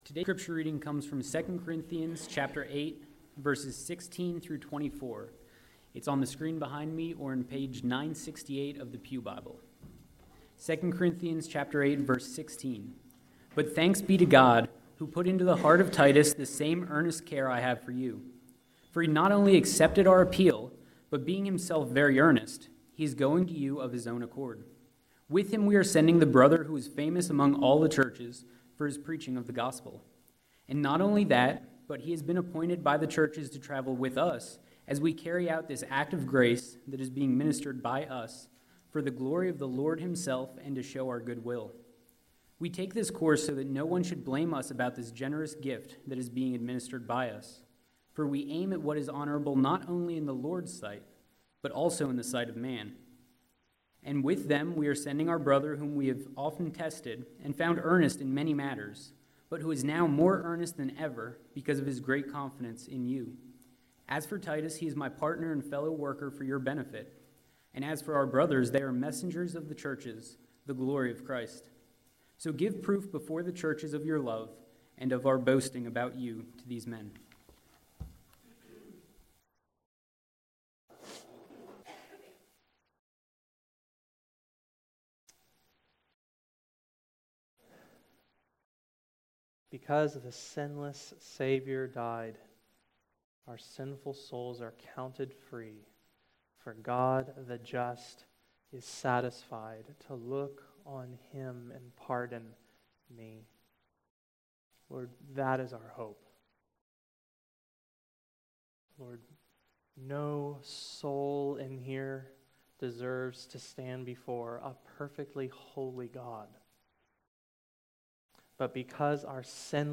The sermon continues the verse by verse series through 2 Corinthians.